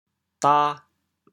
How to say the words 打 in Teochew？
da2.mp3